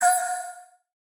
Minecraft Version Minecraft Version snapshot Latest Release | Latest Snapshot snapshot / assets / minecraft / sounds / mob / allay / item_thrown1.ogg Compare With Compare With Latest Release | Latest Snapshot
item_thrown1.ogg